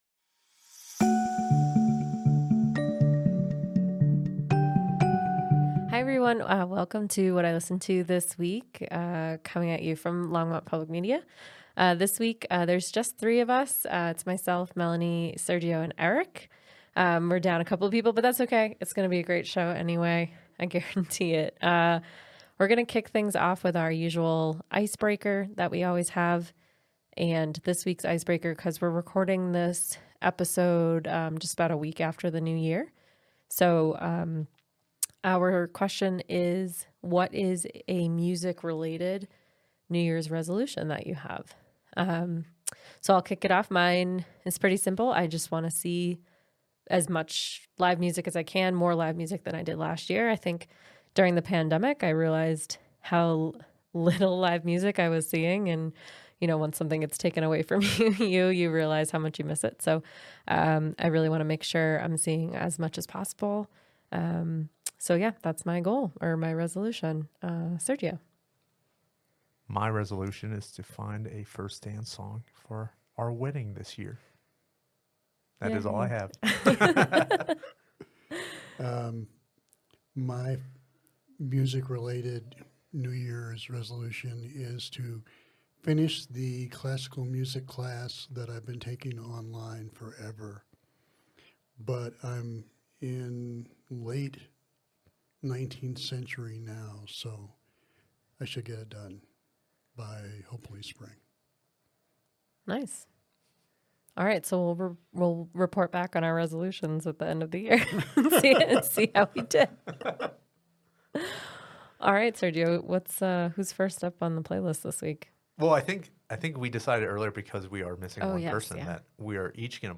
In this episode, our panelists dive deep into each track, offering insightful discussions and passionate reflections that will ignite your love for music.